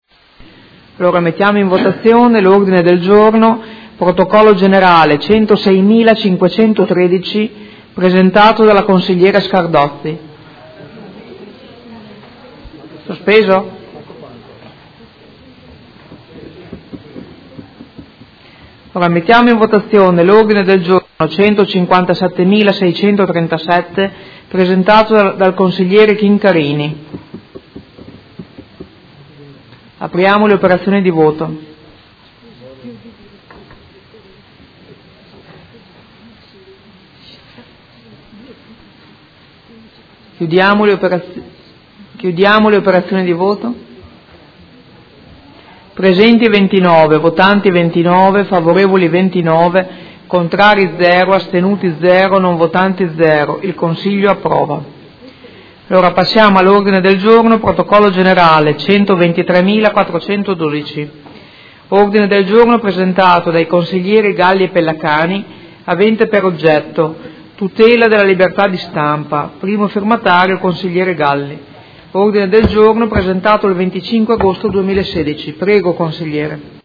Seduta del 27/10/2016 Mette ai voti Ordine del Giorno 157637. Approvato.
Presidentessa